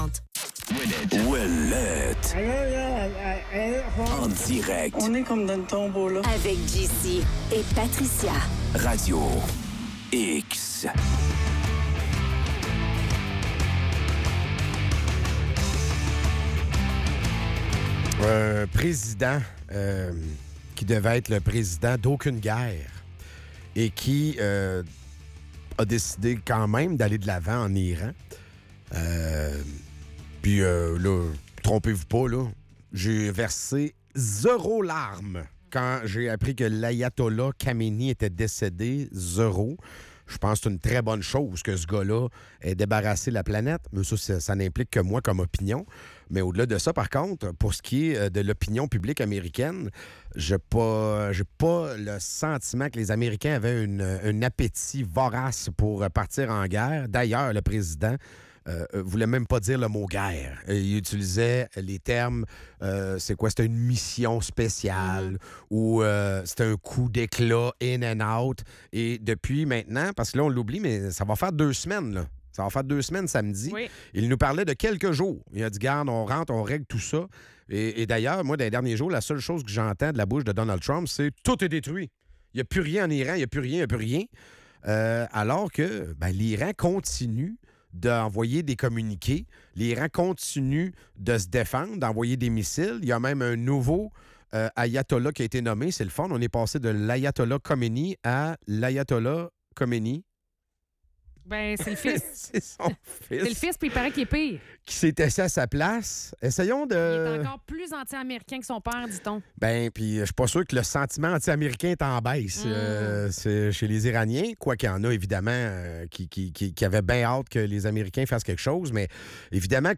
En chronique